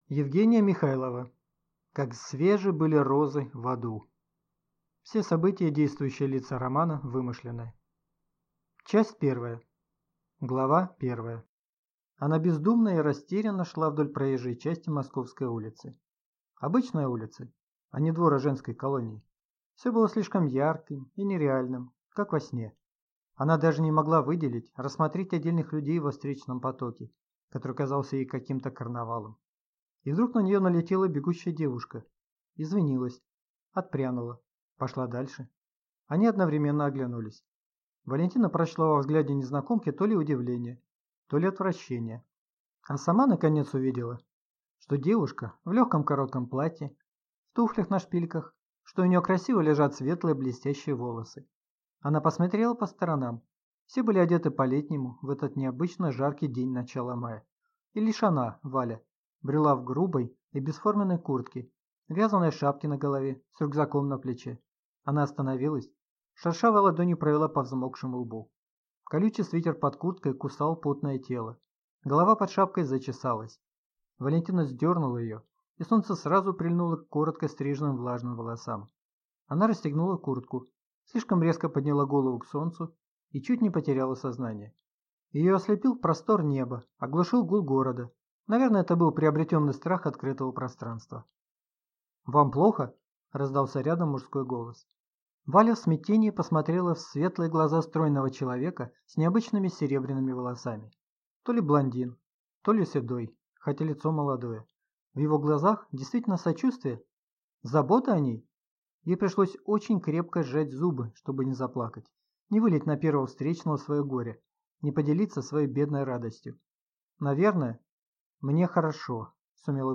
Аудиокнига Как свежи были розы в аду | Библиотека аудиокниг